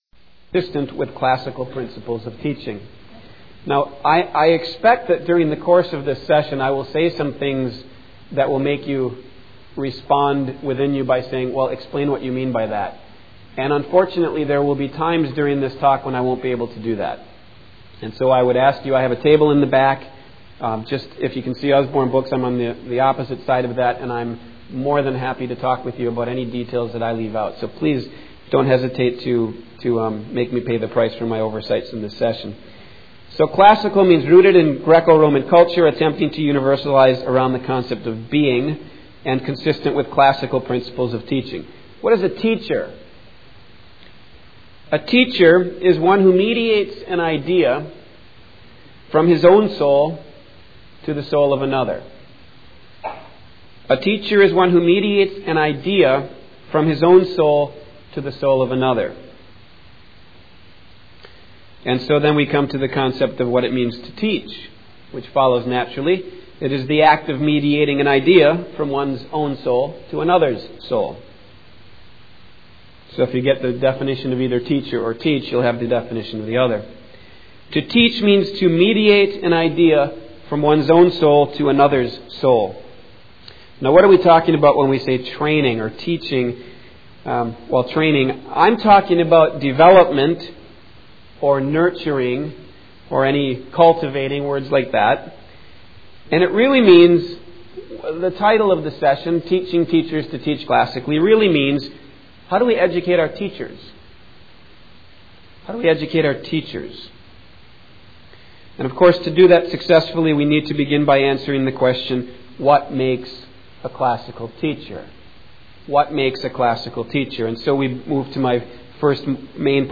2000 Workshop Talk | 0:46:32 | All Grade Levels, General Classroom
Mar 11, 2019 | All Grade Levels, Conference Talks, General Classroom, Library, Media_Audio, Workshop Talk | 0 comments
The Association of Classical & Christian Schools presents Repairing the Ruins, the ACCS annual conference, copyright ACCS.